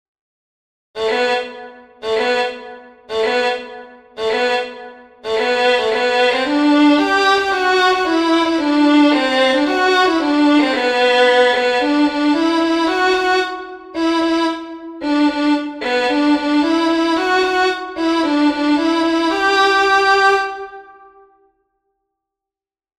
Musiche digitali in mp3 tratte dagli spartiti dell'opuscolo